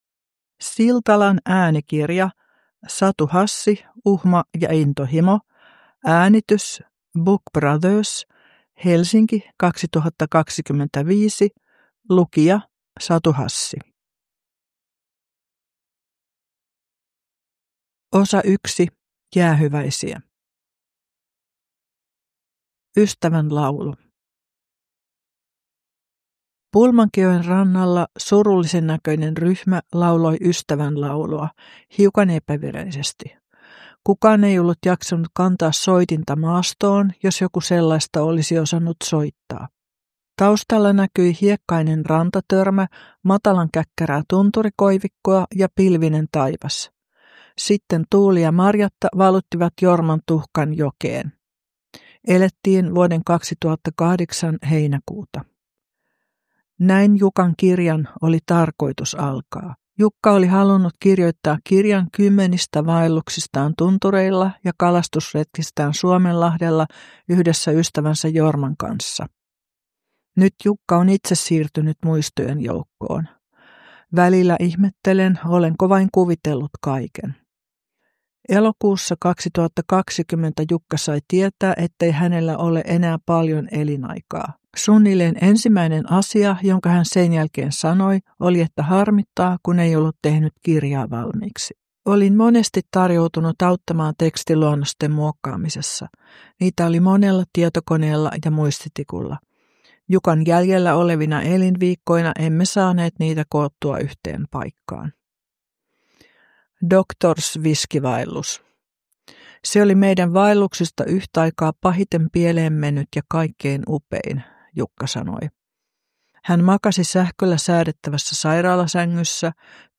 Uhma ja intohimo – Ljudbok
Uppläsare: Satu Hassi